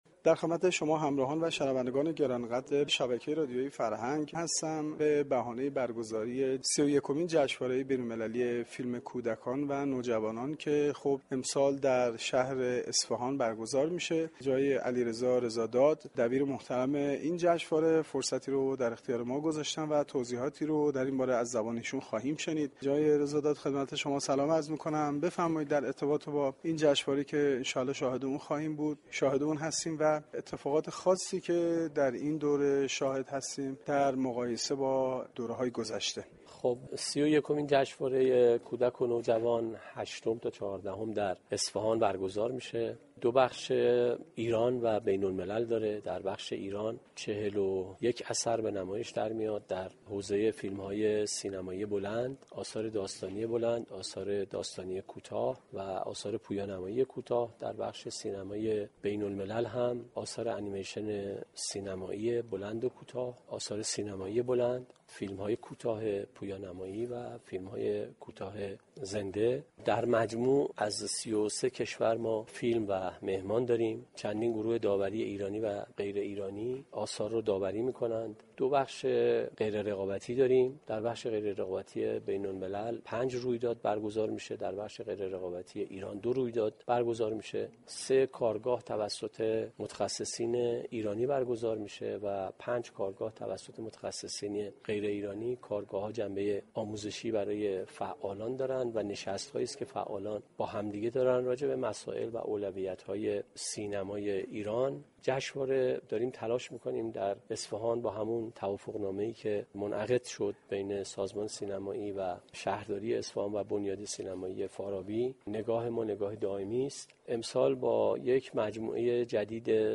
در گفتگوی اختصاصی با گزارشگر رادیو فرهنگ